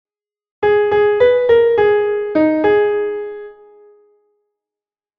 Esta frase ten comezo acéfalo. 6/8: silencio de negra, 4 corcheas
acefalo_1.mp3